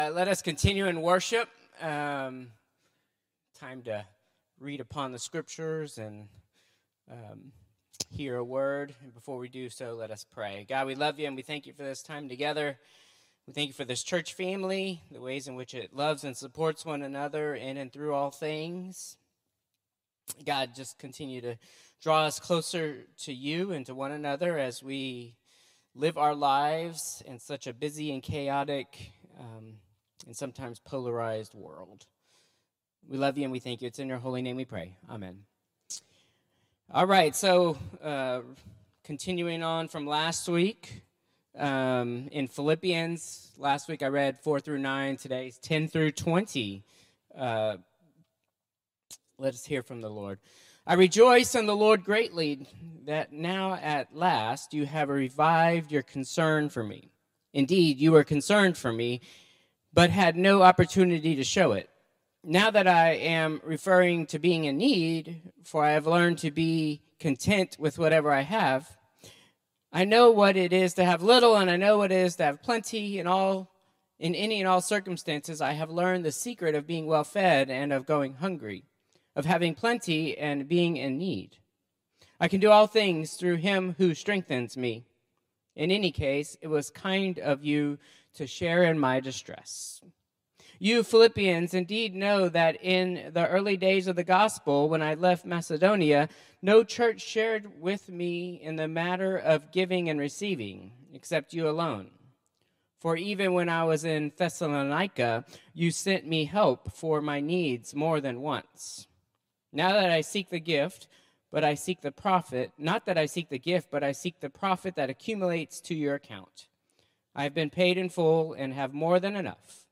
Contemporary Service 11/24/2024